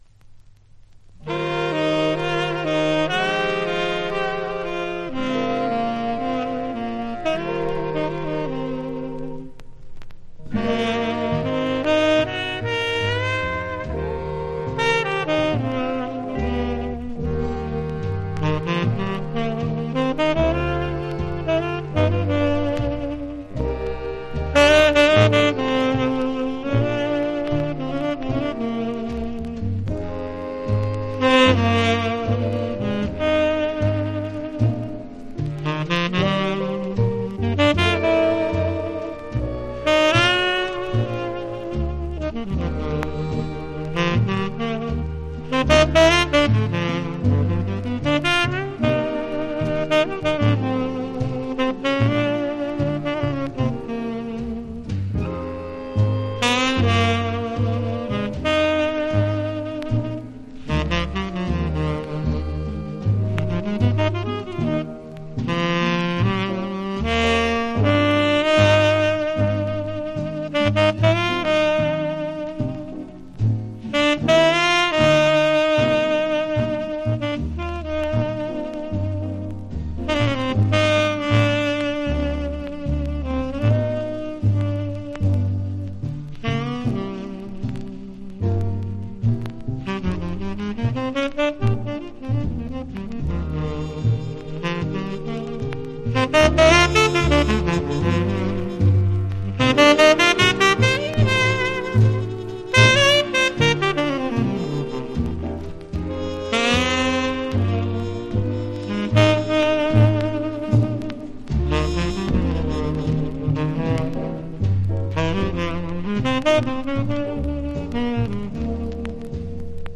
（プレス・小傷によりチリ、プチ音、サーノイズある曲あり…